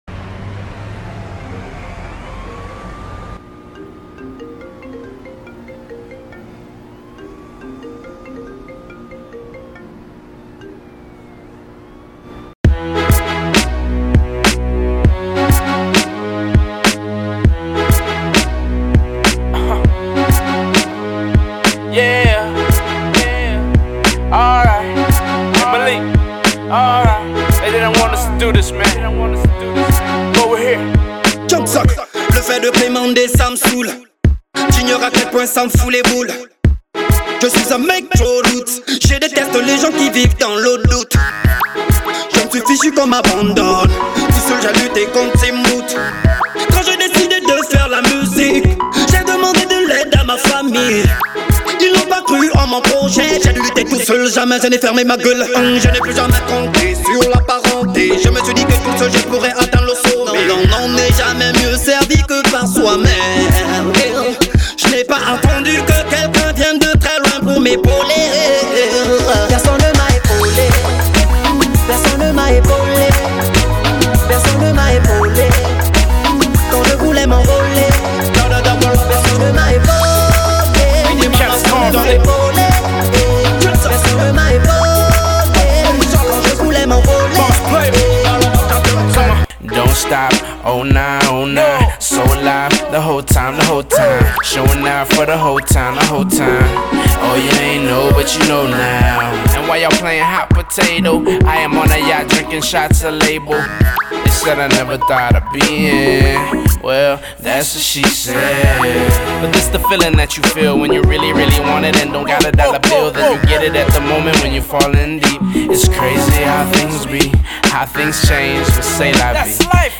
Genre : R&B